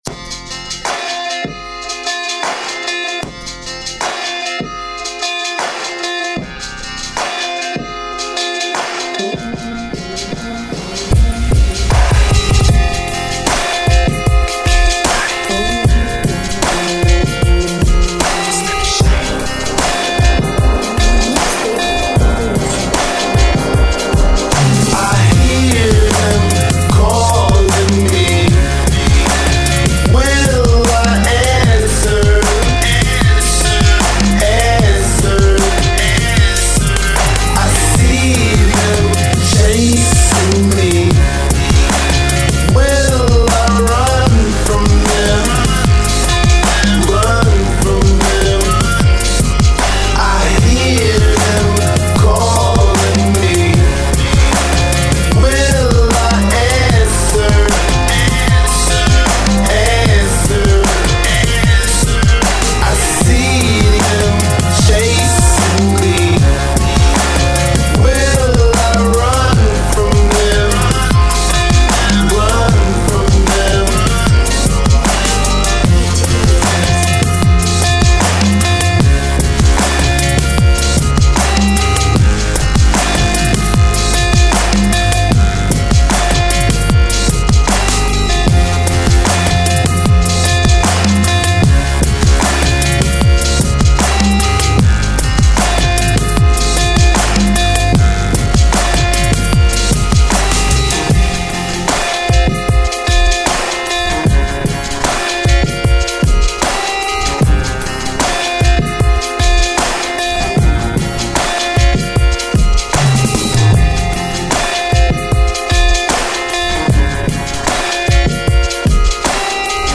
Категория: Dubstep